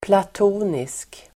Uttal: [²plat'o:nisk]
platonisk.mp3